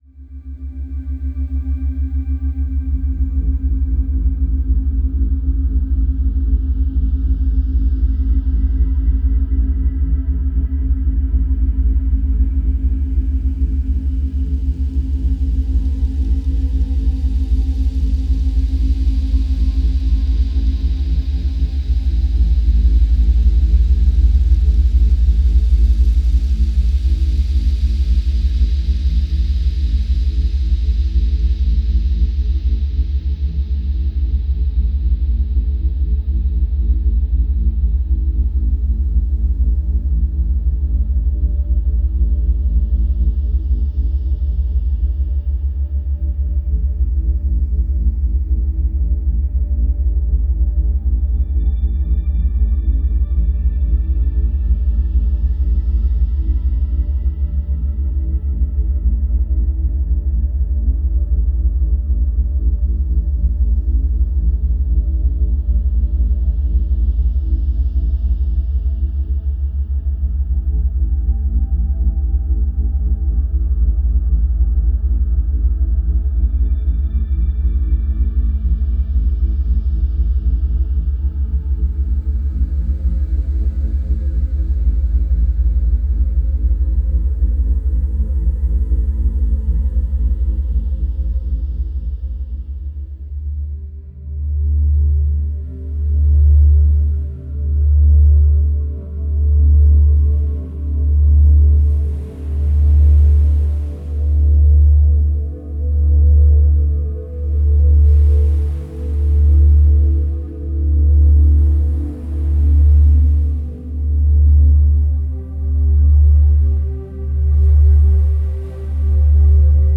Fréquences Alpha, Bêta & Gamma
Neuro-Sons à Fréquence Ciblée